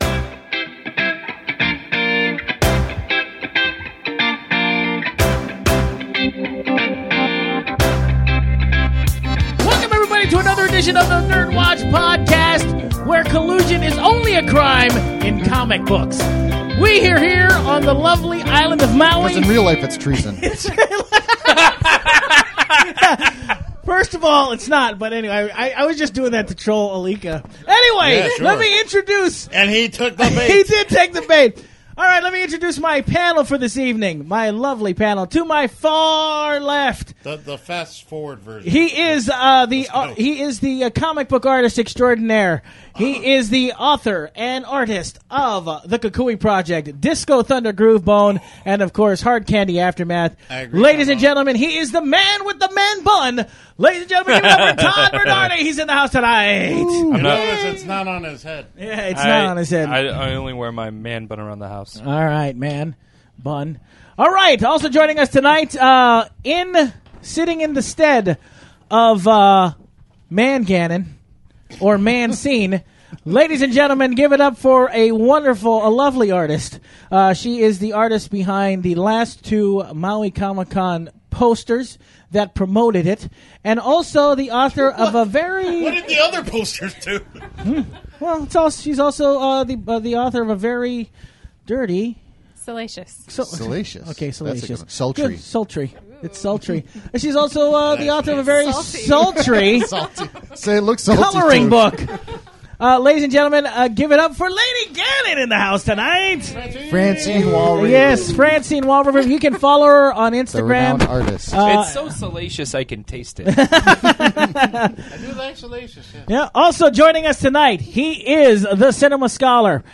All this recorded live at Maui Comics & Collectibles.